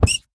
1.21.5 / assets / minecraft / sounds / mob / rabbit / hurt2.ogg
hurt2.ogg